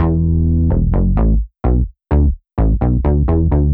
Index of /musicradar/french-house-chillout-samples/128bpm/Instruments
FHC_SulsaBass_128-E.wav